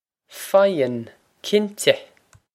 Pronunciation for how to say
Fie-on, kin-teh!
This is an approximate phonetic pronunciation of the phrase.